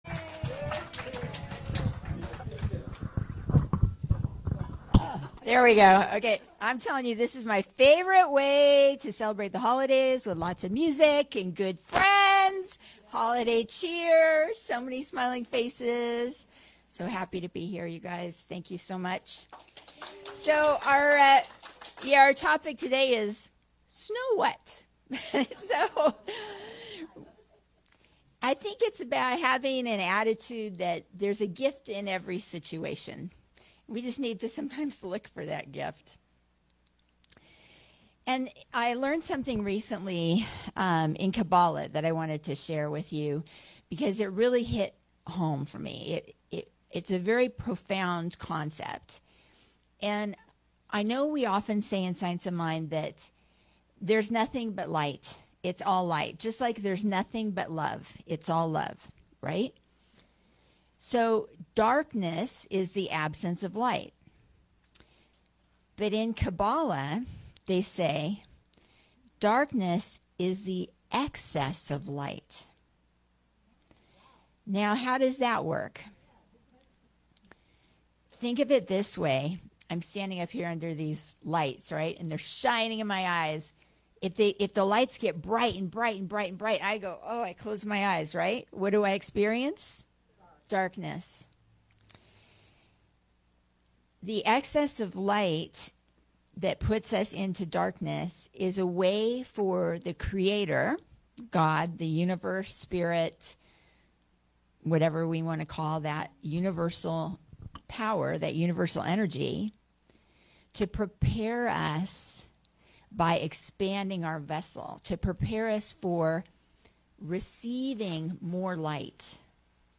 Snow What- talk at CSL Pleasant Valley